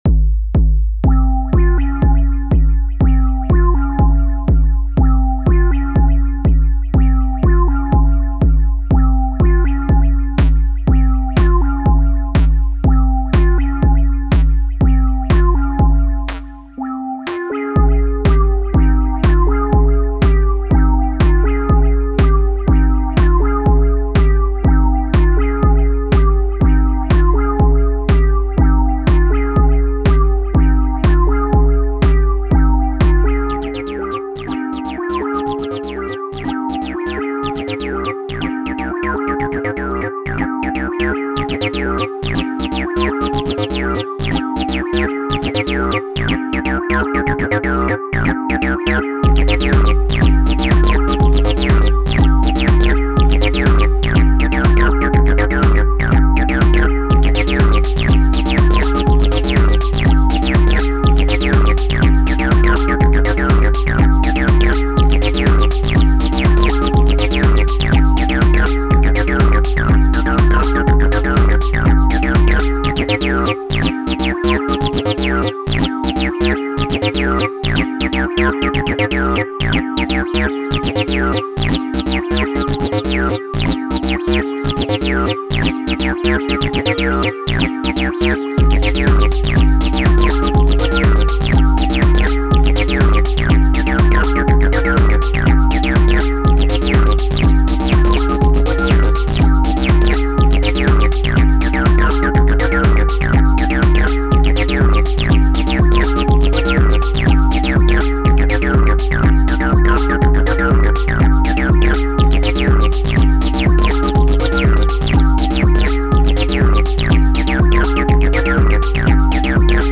6 tracks
Kick, hat and bassline through FX-track with max drive.
Straight from Syntakt and still in ACID-mode :slightly_smiling_face: